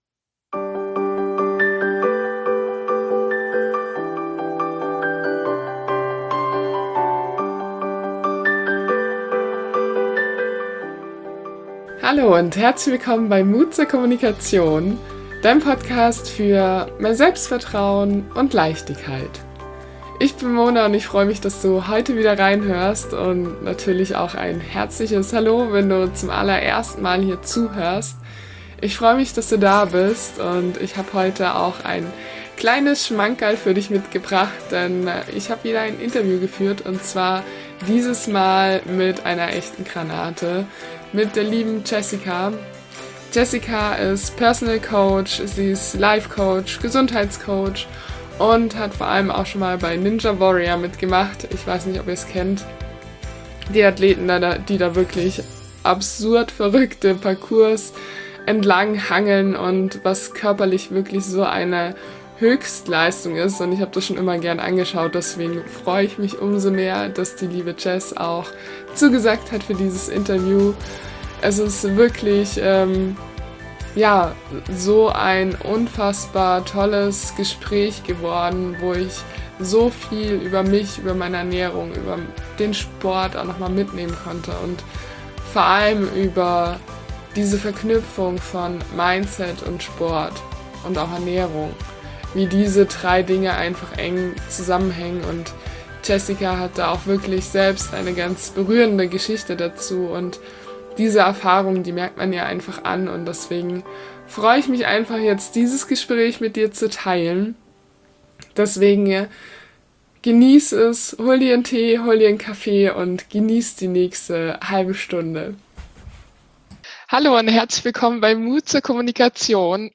#20 Wie du lernen kannst, mit deinem Körper zusammen zu arbeiten - Interview